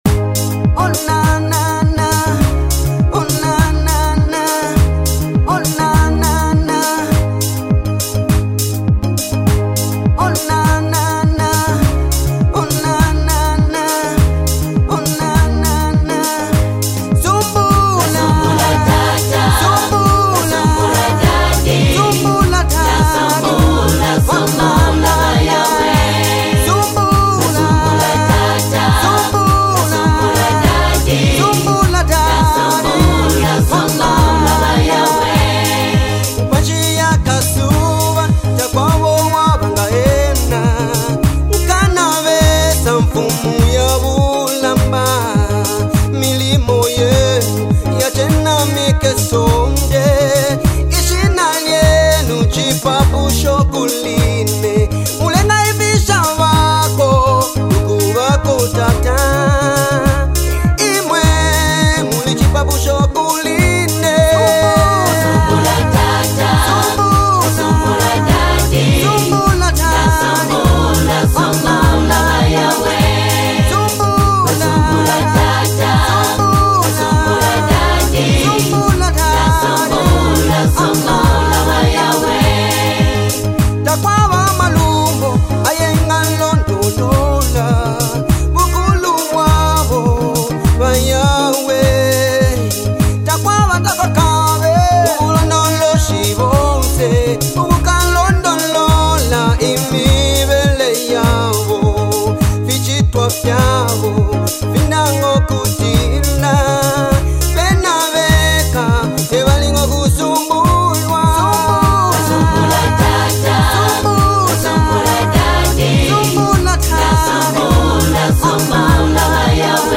passionate vocals
dynamic instrumentation
uplifting sounds